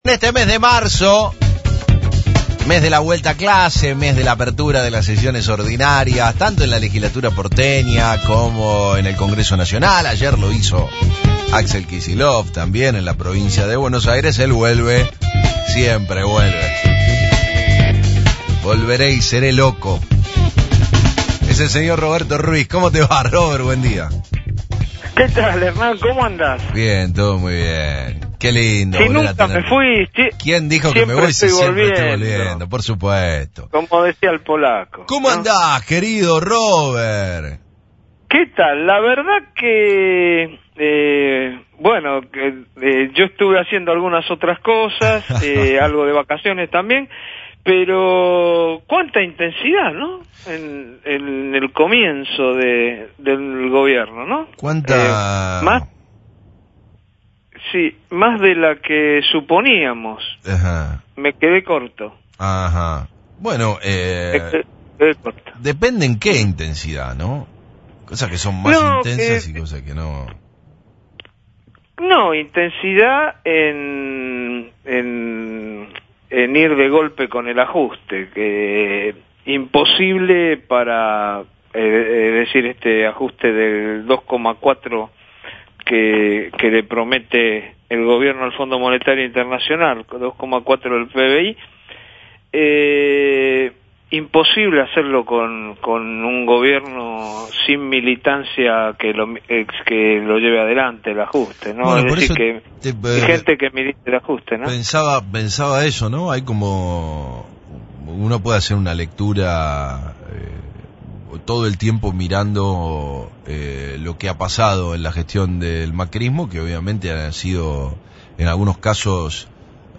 En diálogo con FRECUENCIA ZERO